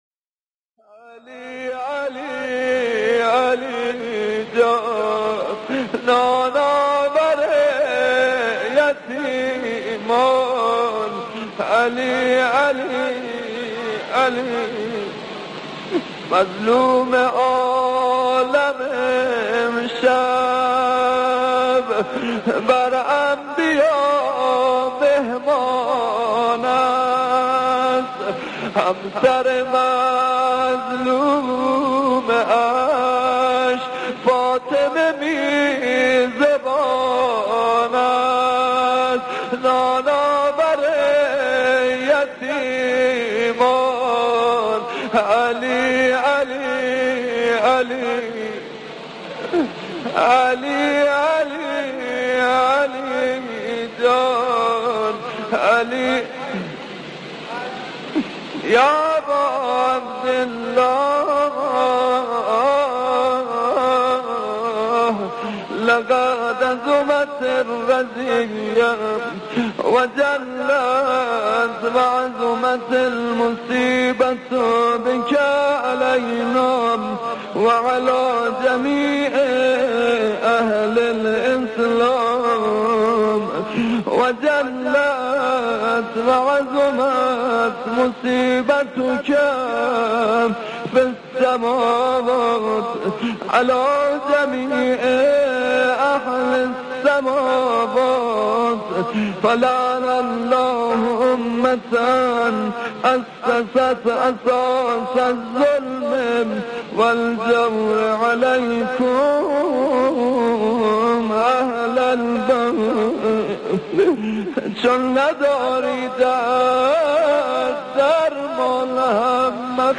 خیمه گاه - هیئت مجازی حبیب بن مظاهر(ع) - مدح - نان آور یتیمان